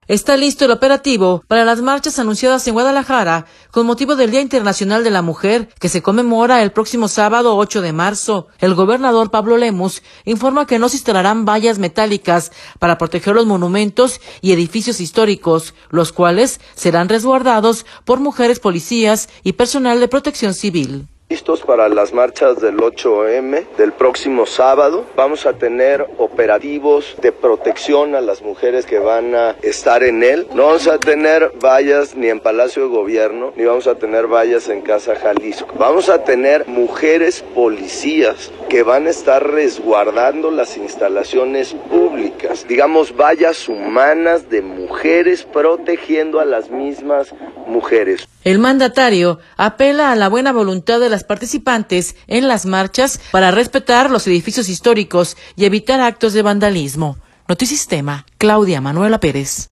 Está listo el operativo para las marchas anunciadas en Guadalajara con motivo del Día Internacional de la Mujer, que se conmemora el próximo sábado 8 de marzo. El gobernador, Pablo Lemus, informa que no se instalarán vallas metálicas para proteger los monumentos y edificios históricos, los cuales serán resguardados por mujeres policías y personal de Protección Civil.